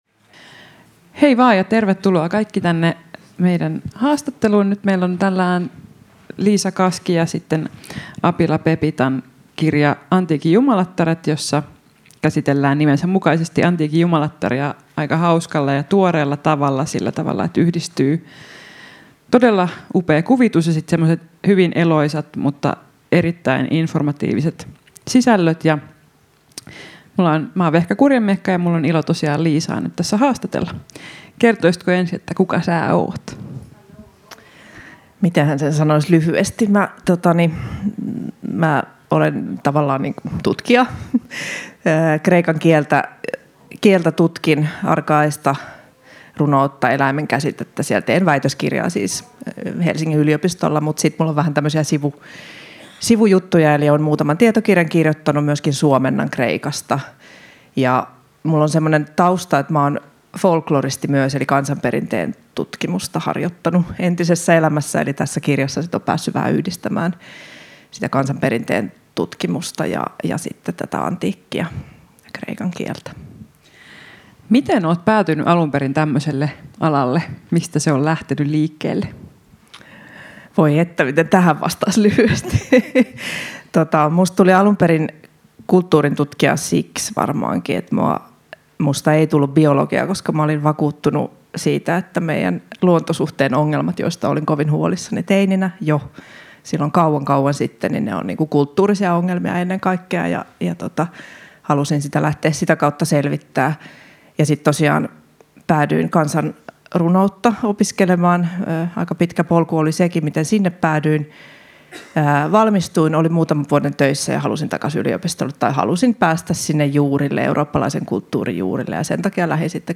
Ohjelma on taltioitu Tampereen Kirjafestareilla 2024. https